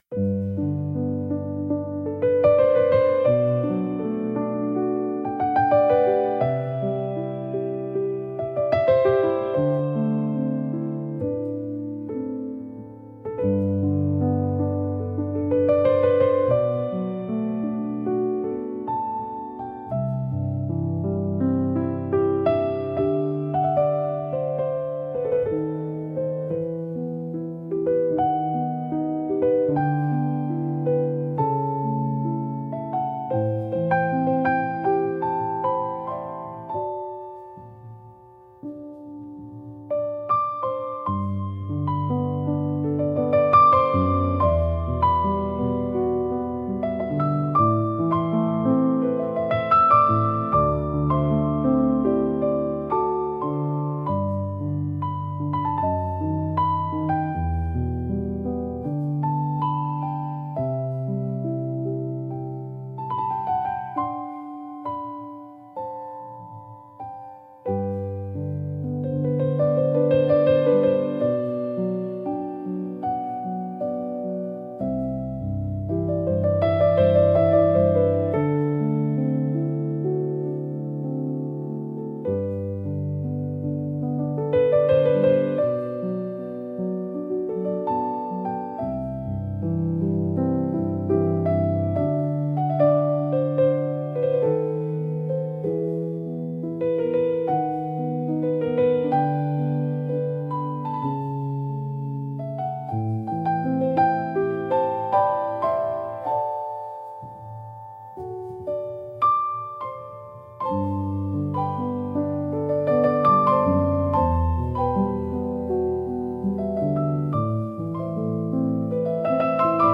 聴く人にやすらぎと温かみを提供し、心を穏やかに整える効果があります。繊細で情感豊かな空気を醸し出すジャンルです。